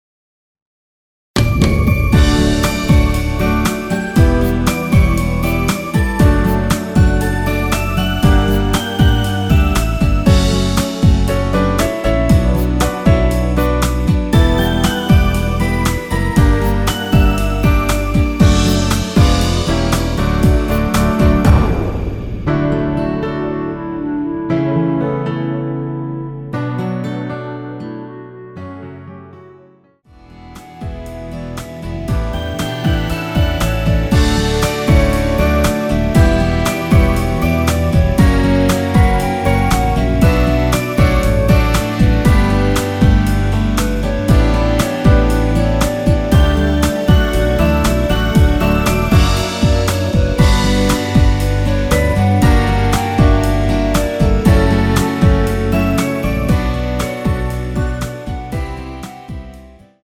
원키에서(-1)내린 멜로디 포함된 1절후 바로 후렴부분으로 진행되게 편곡 하였습니다.
D
앞부분30초, 뒷부분30초씩 편집해서 올려 드리고 있습니다.
중간에 음이 끈어지고 다시 나오는 이유는
(멜로디 MR)은 가이드 멜로디가 포함된 MR 입니다.